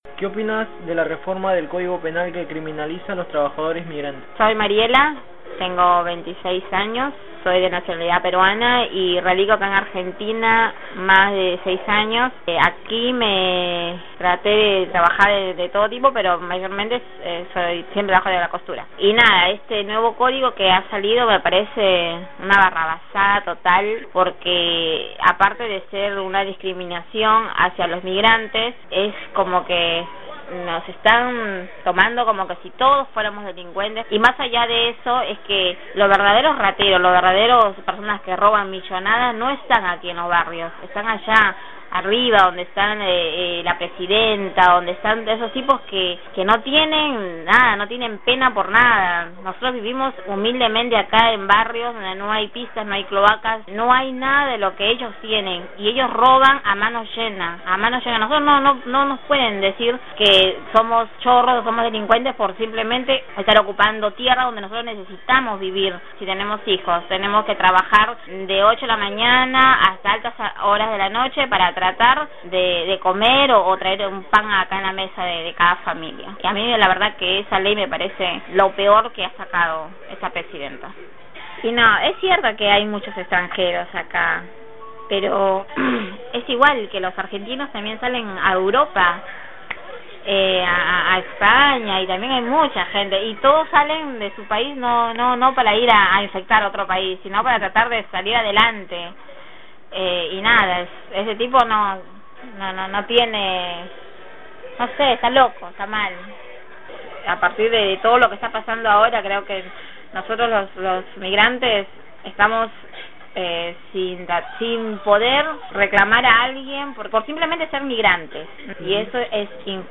Las voces que podés escuchar más abajo son de mujeres y jóvenes migrantes del Barrio JL Cabezas de La Plata, uno de los más castigados por la inundación del año pasado.